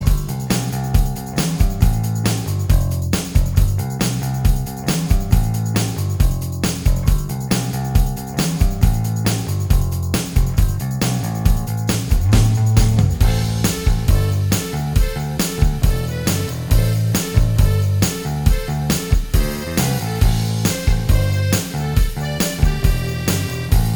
Minus Guitars Pop (1980s) 3:19 Buy £1.50